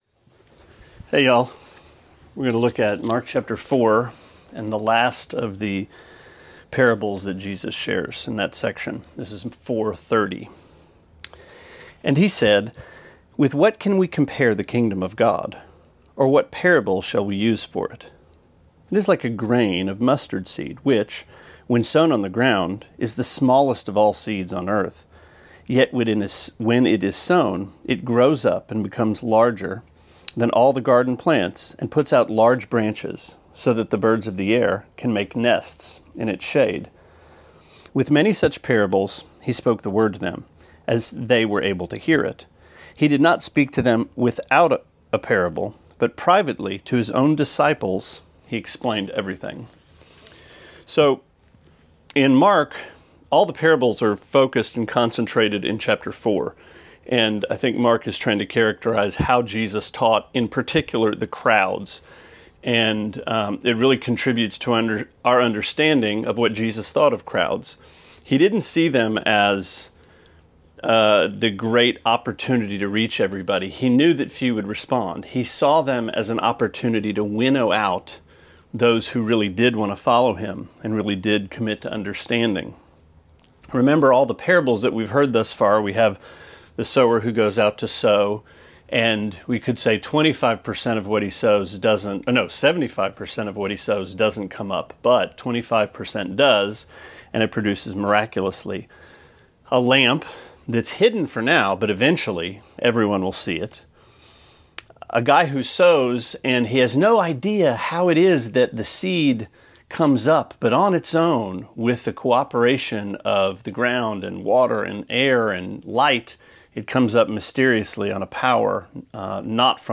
Sermonette 6/30: Mark 4:30-34: Seed, Seed, Seed